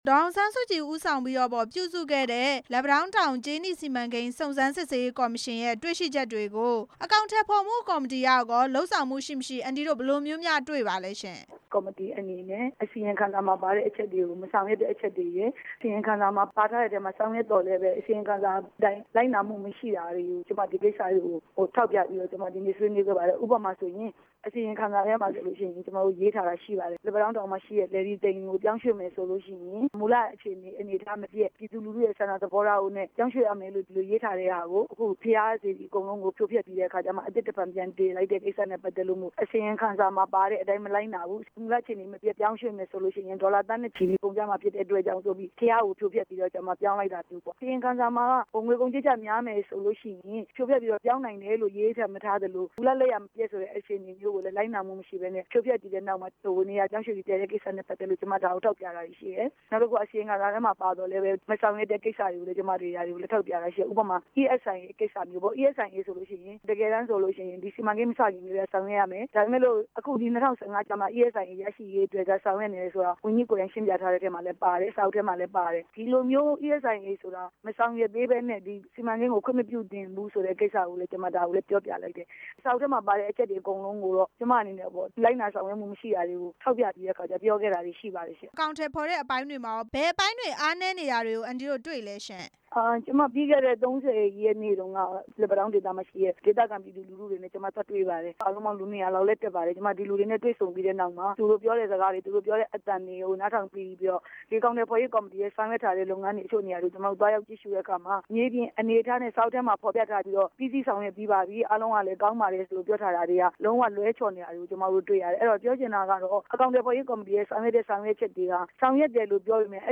လက်ပံတောင်းတောင် အစီရင် ခံစာ အကြောင်း ဒေါ်ခင်စန်းလှိုင် နဲ့ မေးမြန်းချက်